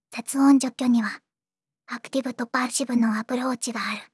voicevox-voice-corpus / ROHAN-corpus /ずんだもん_ヒソヒソ /ROHAN4600_0043.wav